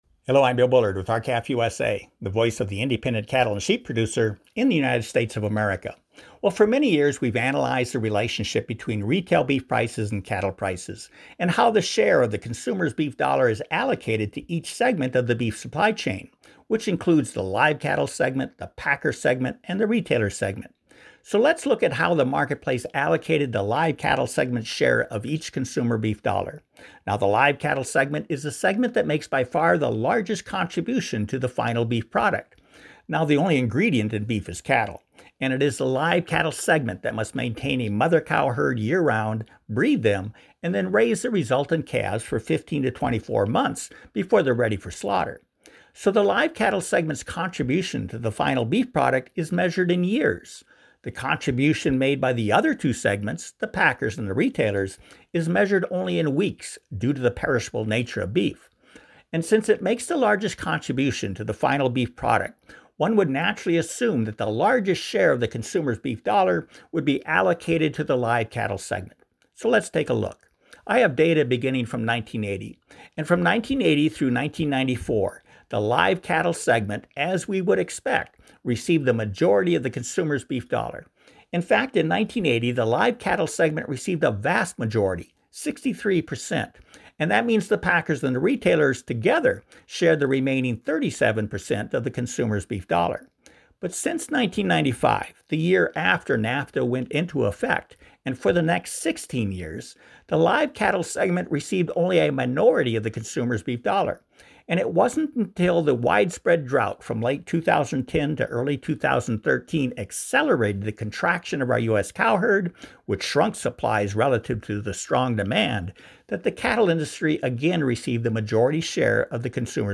R-CALF USA’s weekly opinion/commentary educates and informs both consumers and producers about timely issues important to the U.S. cattle and sheep industries and rural America.